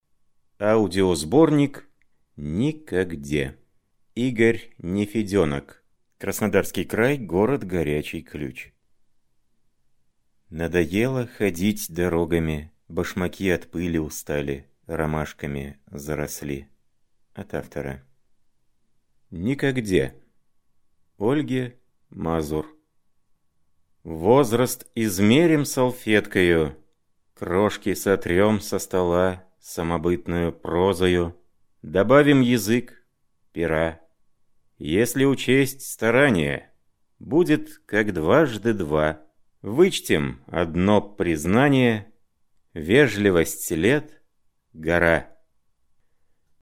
Аудиокнига Никогде | Библиотека аудиокниг